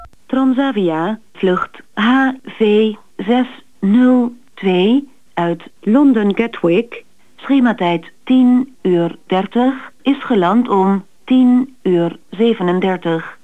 Voice Response Consultancy
- vluchttijden informatie Schiphol (Klik
schipholdemo.wav